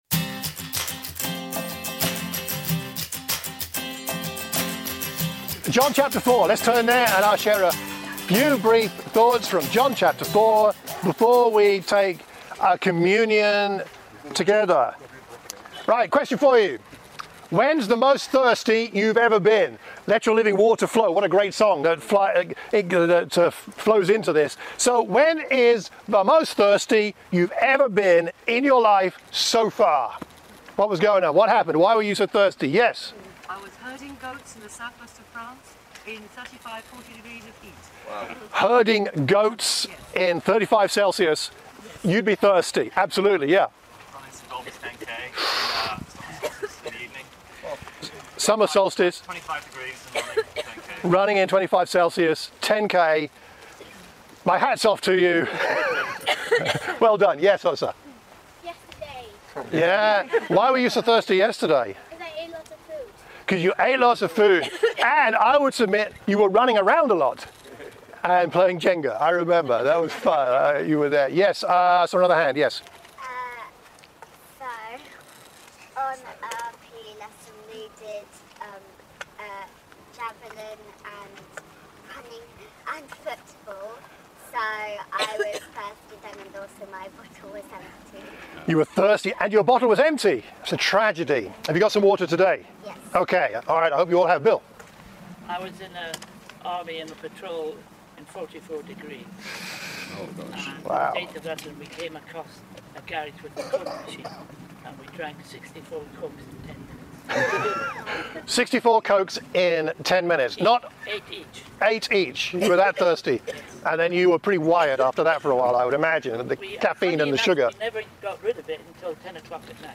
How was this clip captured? We took ourselves off to Cassiobury Park for an outdoor service. On an exceptionally hot day it seemed only appropriate to discuss the topic of thirst from John 4.